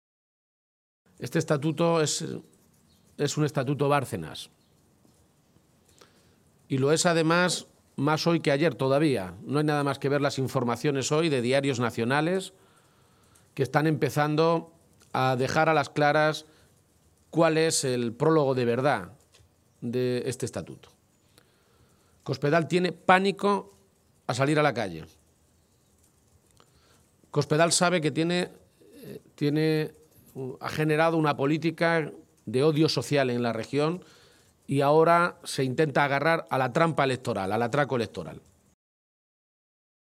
El secretario general del PSOE de Castilla-La Mancha, Emiliano García-Page, ha protagonizado esta mañana un desayuno informativo en Toledo con medios de comunicación en el que ha anunciado la presentación de un recurso ante el Tribunal Constitucional contra la reforma del Estatuto de Autonomía de Castilla-La Mancha aprobada ayer en el Senado solo con los votos del PP “para parar este verdadero atropello, este verdadero pucherazo electoral que quiere dar Cospedal y que es el único motivo de este cambio estatutario”.